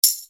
TAMB 01W.wav